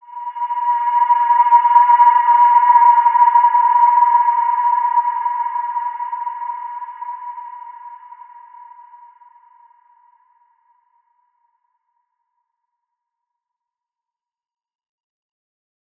Large-Space-B5-mf.wav